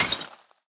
Minecraft Version Minecraft Version 1.21.5 Latest Release | Latest Snapshot 1.21.5 / assets / minecraft / sounds / block / suspicious_sand / break5.ogg Compare With Compare With Latest Release | Latest Snapshot
break5.ogg